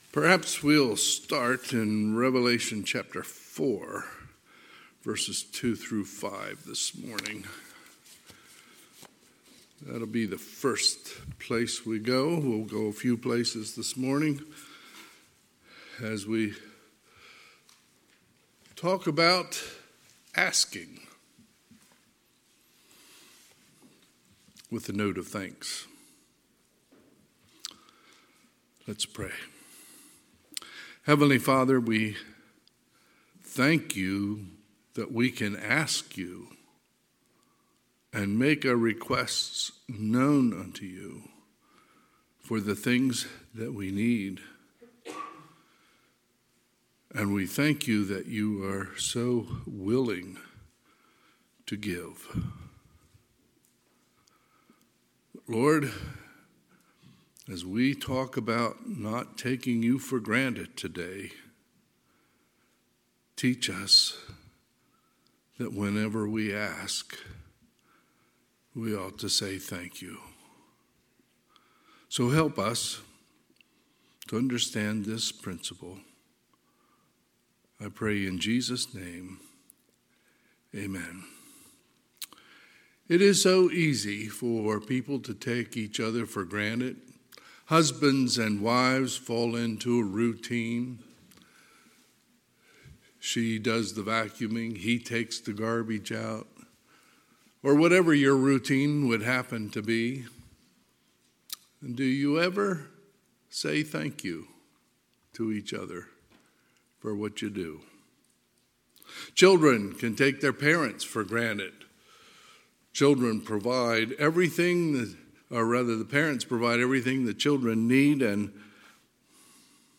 Sunday, November 27, 2022 – Sunday AM